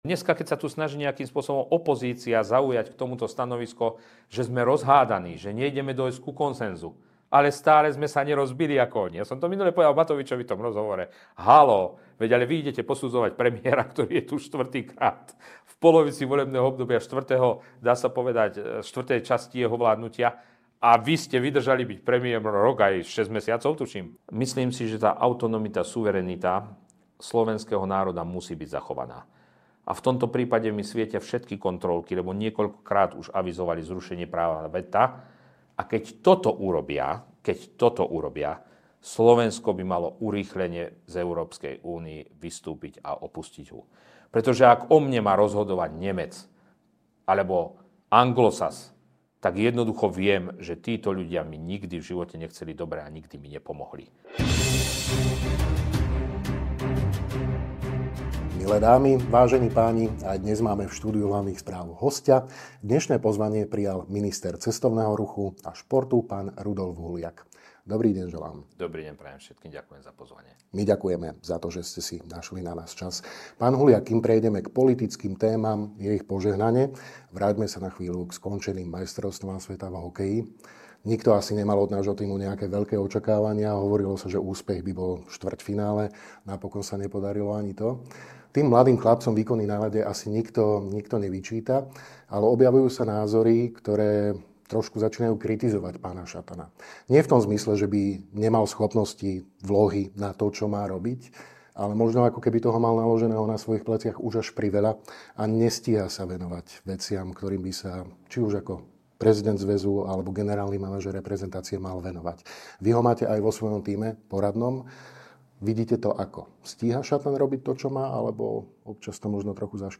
Nielen rezortné témy, ale aj domácu a zahraničnú politiku, bezpečnosť, potravinovú sebestačnosť a budúce smerovanie Slovenska sme prebrali vo videorozhovore s ministrom cestovného ruchu a športu SR, Ing. Rudolfom Huliakom.